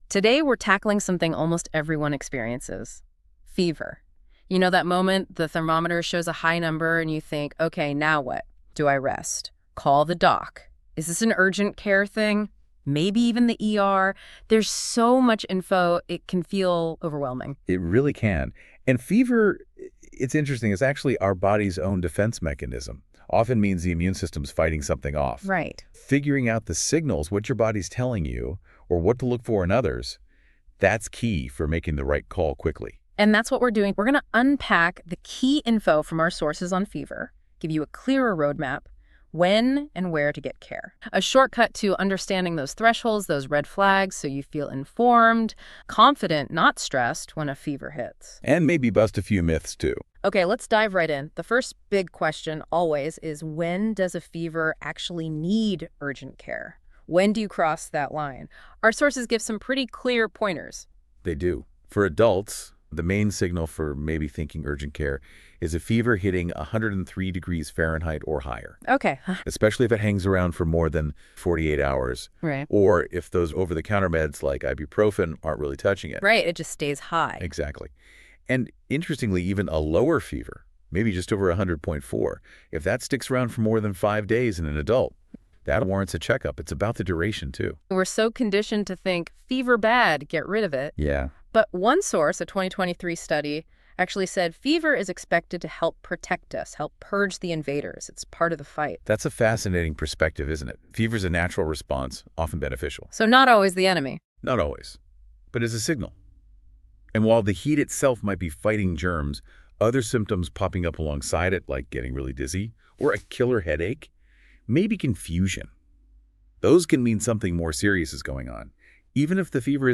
Listen to a discussion on getting help for your fever When to go to the urgent care for a fever You should visit urgent care if your fever climbs to 103°F or higher and lasts over 48 hours or doesn’t drop with over-the-counter medication.